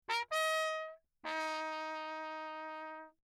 13 Spitzdämpfer (Straight Mutes) für Trompeten im Klangvergleich
Im Rahmen dieser Arbeit wurden verschiedene kurze Sequenzen zunächst ohne Dämpfer und dann mit dreizehn verschiedener Spitzdämpfer im reflexionsarmen Raum der mdw aufgenommen.
Drehventiltrompete
Signal 2
TRP-MUTE_Lechner_Emo1T_signal2.mp3